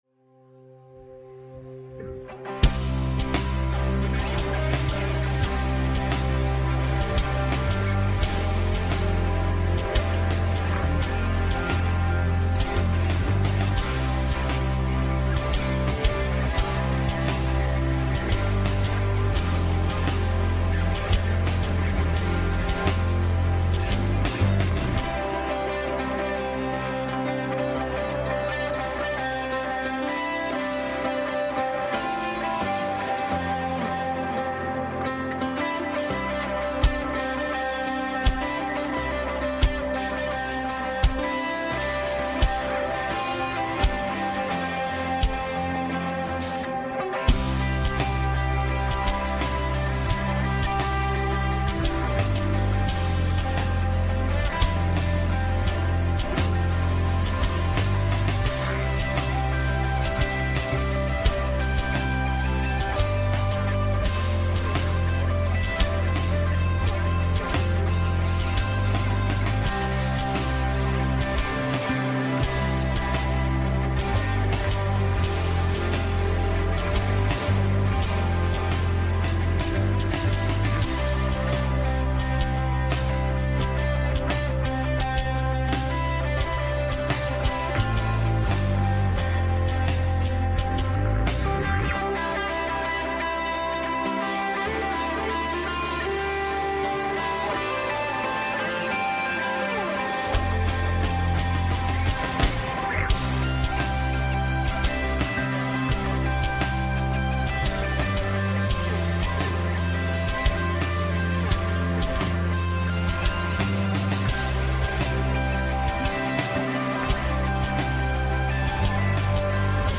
Westgate Chapel Sermons Resolute: Resolute Focus - Luke 9:51-56 Mar 30 2025 | 01:21:11 1x 00:00 / 01:21:11 Subscribe Share Apple Podcasts Overcast RSS Feed Share Link Embed